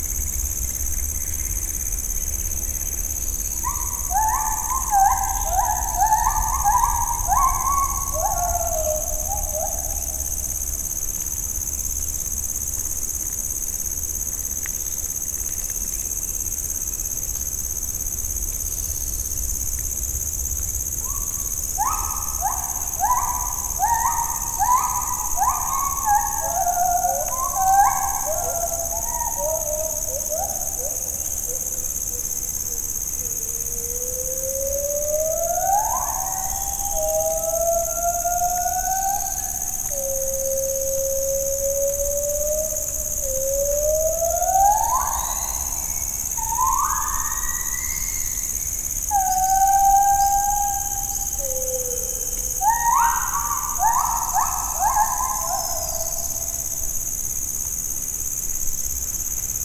The only side road in Panti.
Along this track we heard monkeys 'laughing' (probably just communicating), and you can listen to a 1-minute stereo recording of it